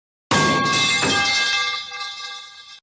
pipe.mp3